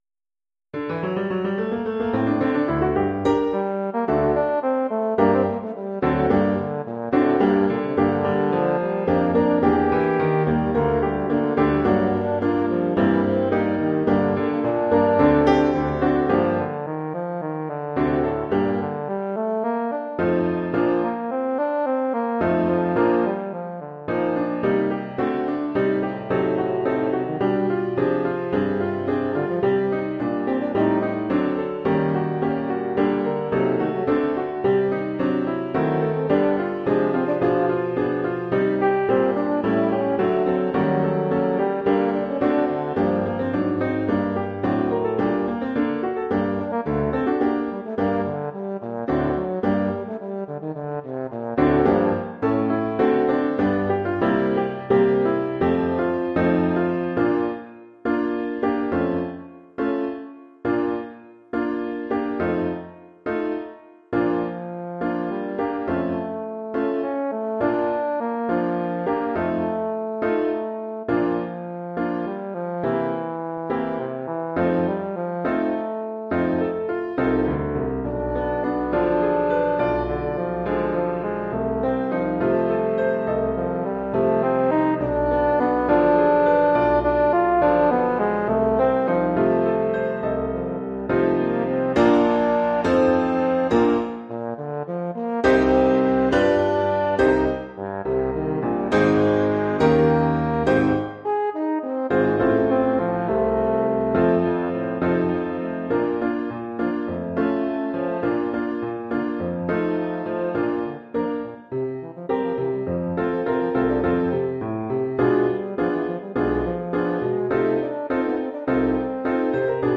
Formule instrumentale : Saxhorn basse/Tuba et piano
Oeuvre pour saxhorn basse / euphonium /
tuba et piano.
avec de riches harmonies et des mélodies flamboyantes